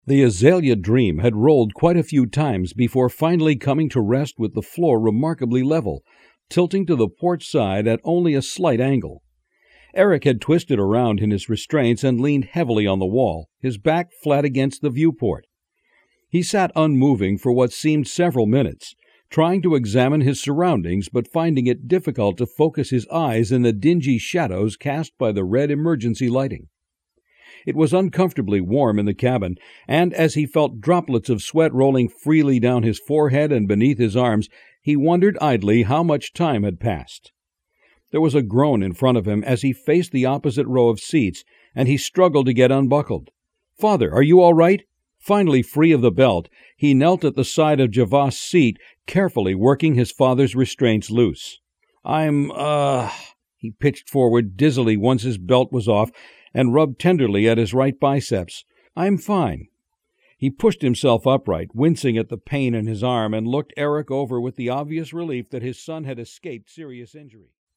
Fiction Audio Book Demo